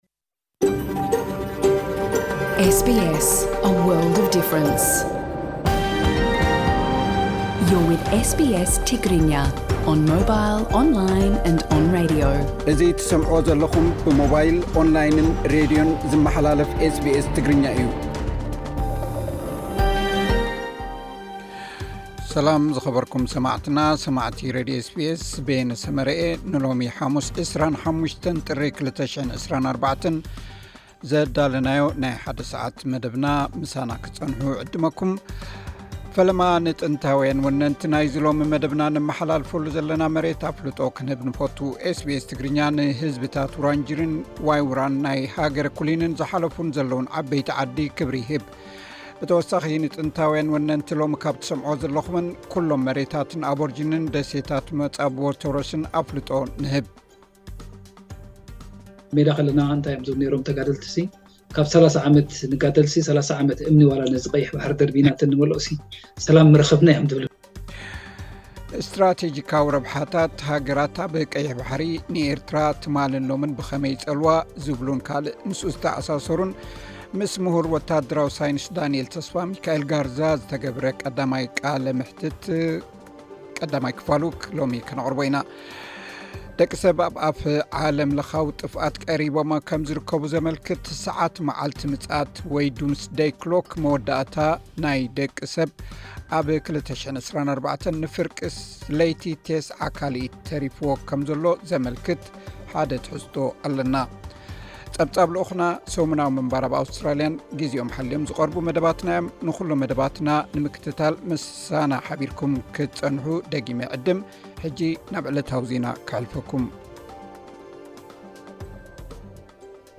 ቀዳማይ ክፋል ቃለ መሕትት